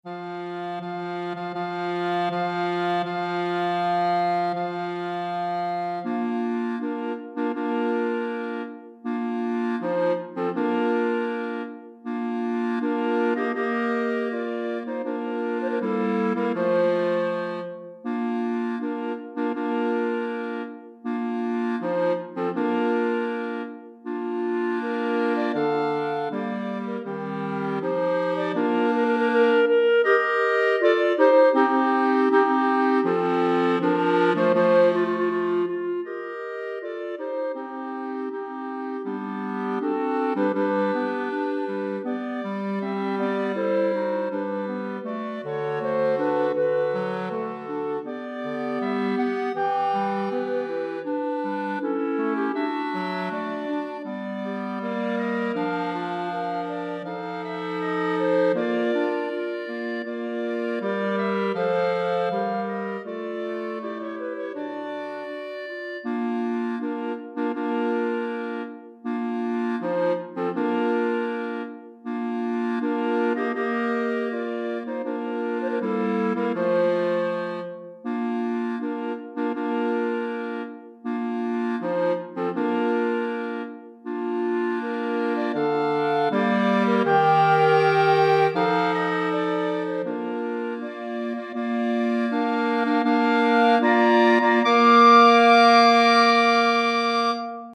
Répertoire pour Clarinette - 4 Clarinettes